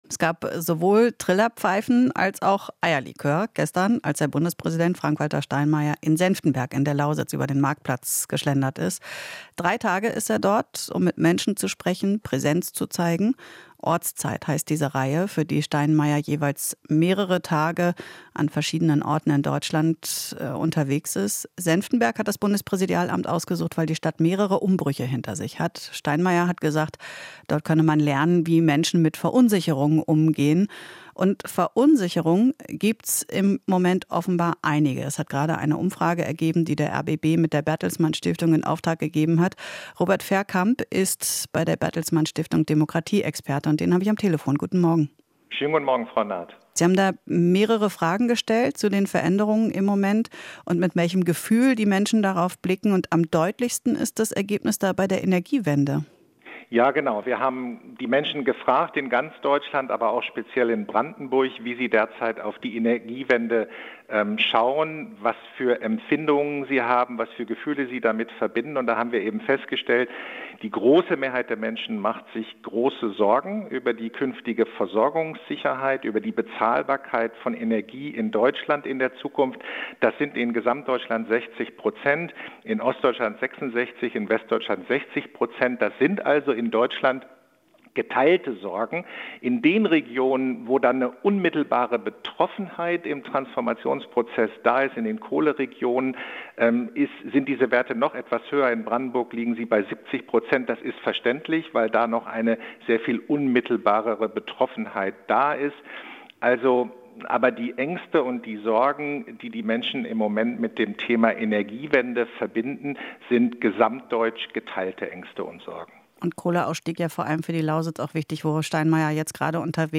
Interview - Ortszeit in Senftenberg: "Man muss Sorgen und Ängste besprechen"